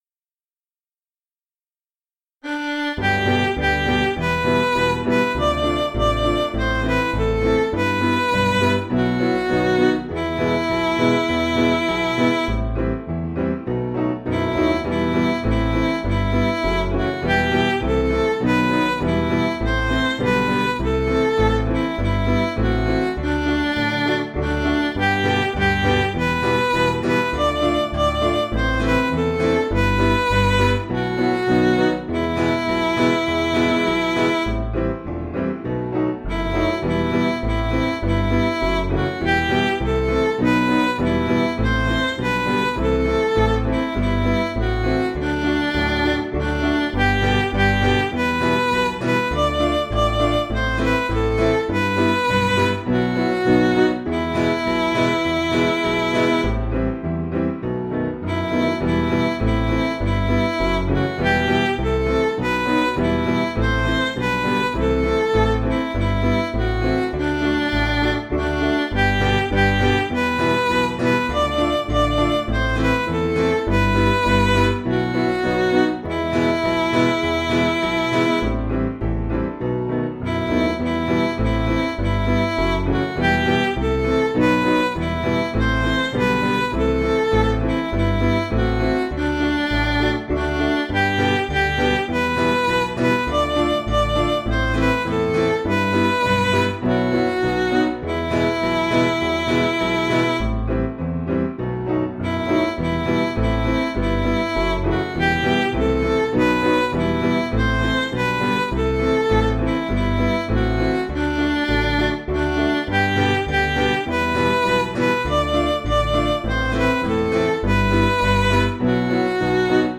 Piano & Instrumental
(CM)   7/Em
Midi